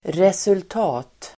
Uttal: [result'a:t]